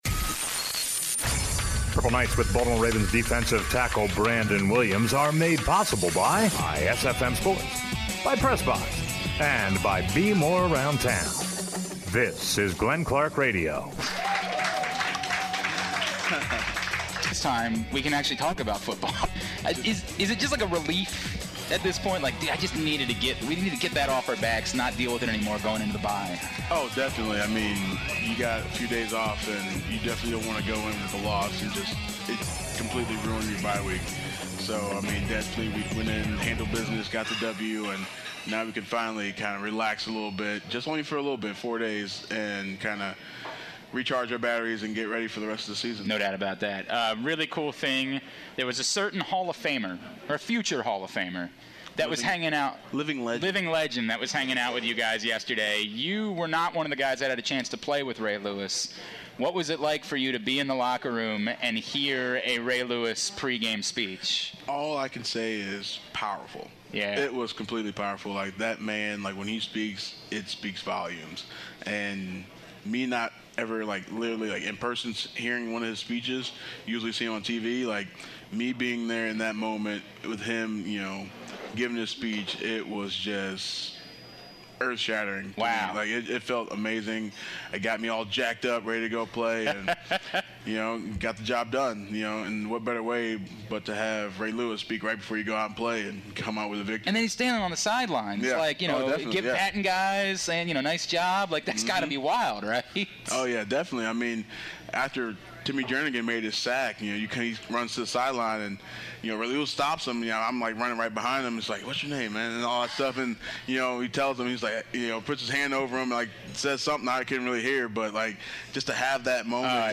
It's a Purple Night with Ravens defensive tackle Brandon Williams at the Greene Turtle in Hunt Valley.